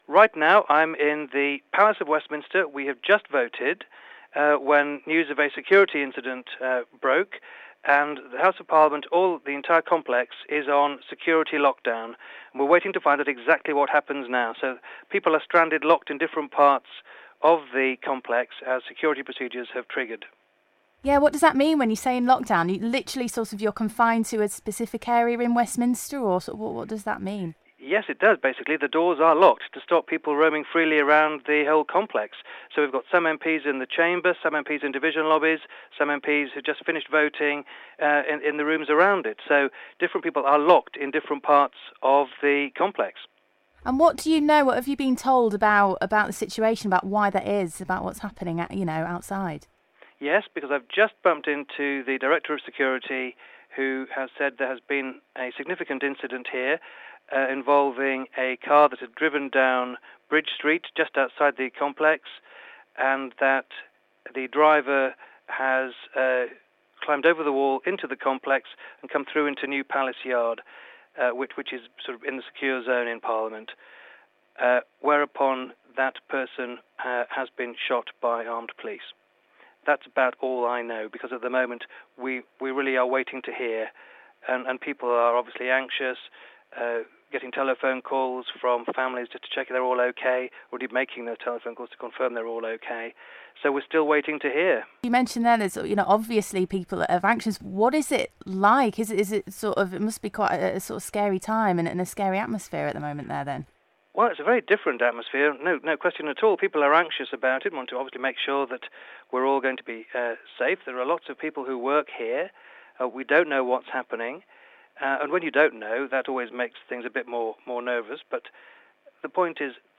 WESTMINSTER ATTACK: Harrogate and Knaresborough's MP speaks to Stray FM from Parliament
Andrew Jones is at the Houses of Parliament, which are in lockdown following a suspected terrorist attack.